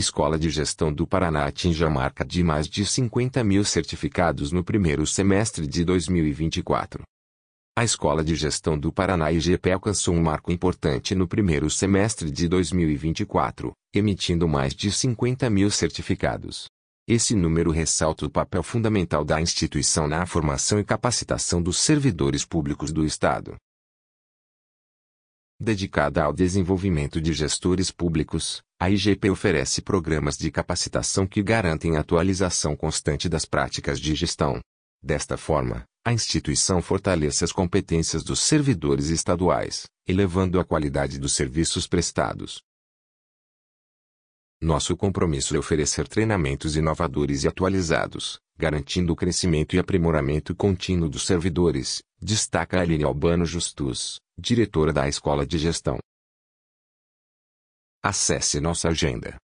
audionoticia_50milcertificados.mp3